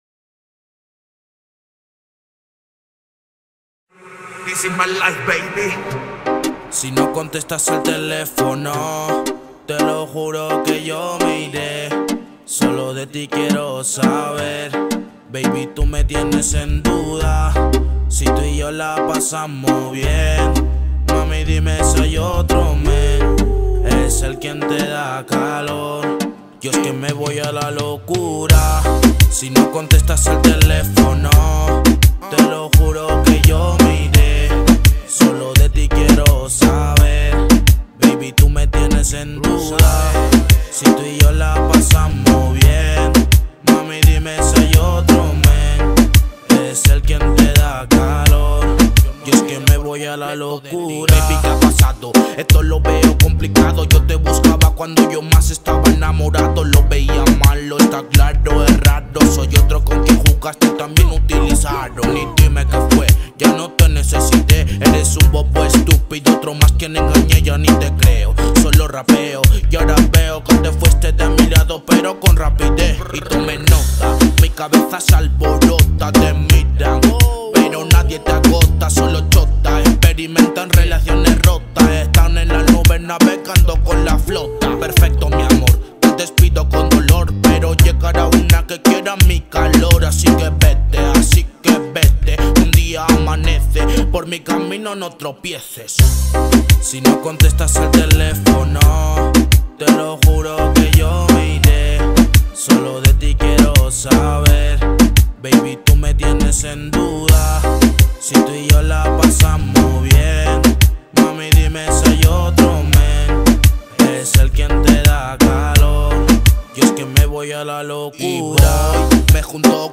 это яркая и динамичная композиция в жанре афробит